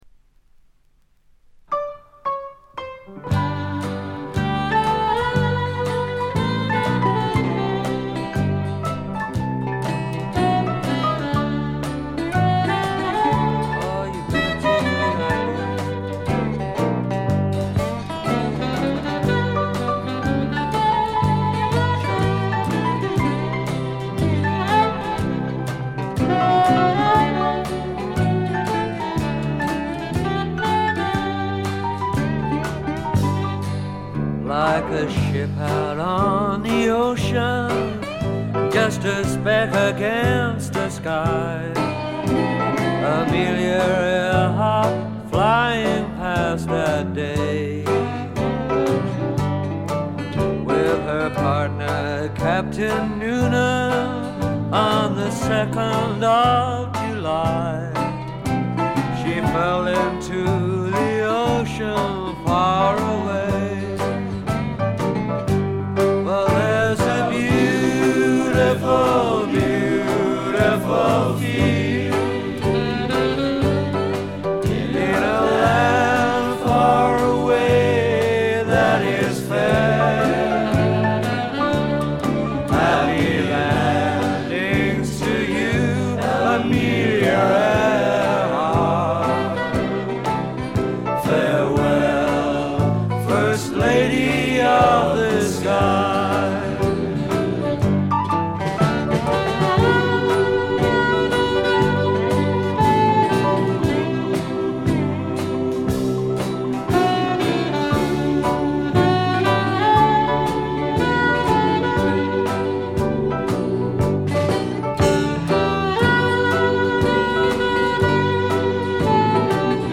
ほとんどノイズ感無し。
試聴曲は現品からの取り込み音源です。
Mixed at IBC.